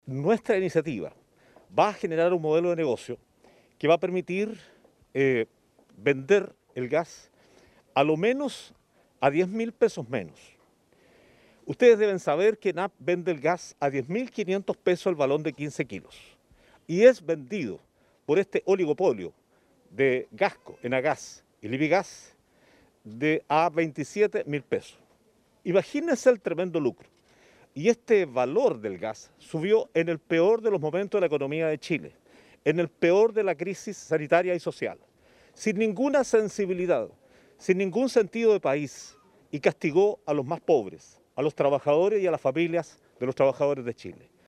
En una línea similar, el alcalde de Chiguayente, Antonio Rivas, señaló que ingresó una solicitud para que el municipio sea un distribuidor directo de gas licuado para las vecinas y vecinos.
cuna-gas-alcalde-rivas.mp3